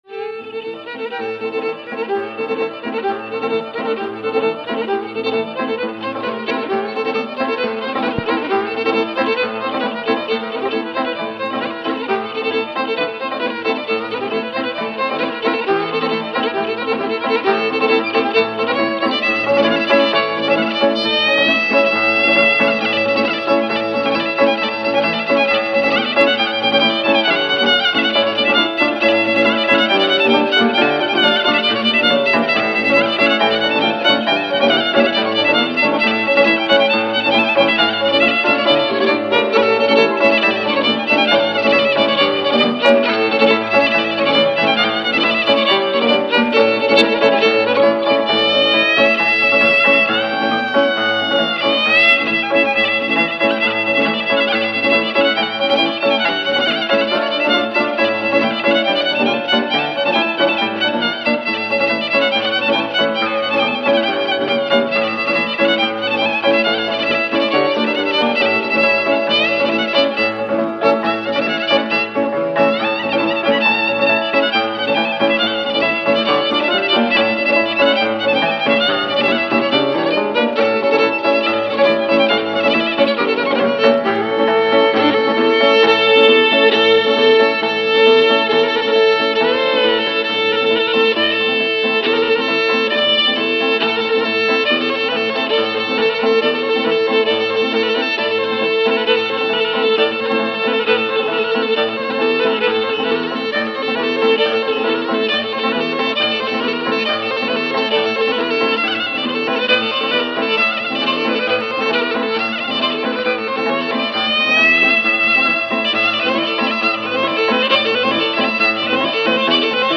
ΤΡΑΓΟΥΔΙΑ ΚΑΙ ΣΚΟΠΟΙ ΑΠΟ ΤΑ ΔΩΔΕΚΑΝΗΣΑ
Σούστα αστυπολίτικη
sousta.mp3